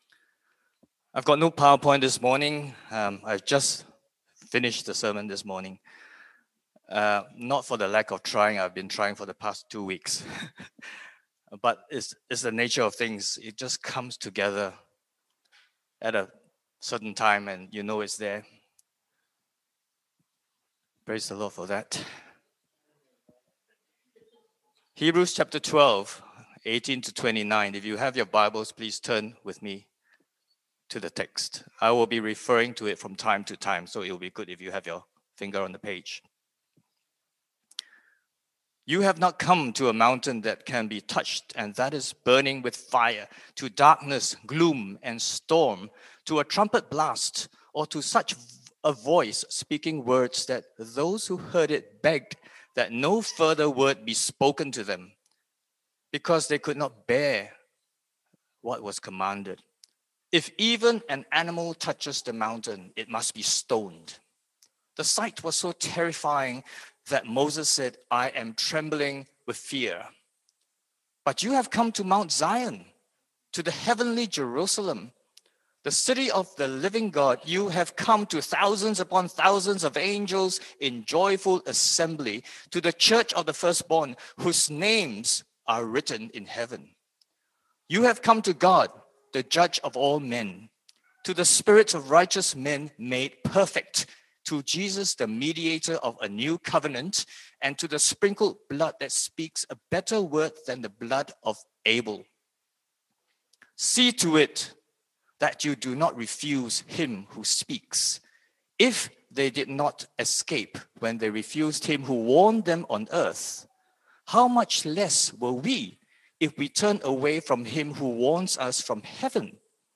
Categories Sermon Leave a Reply Cancel reply Your email address will not be published.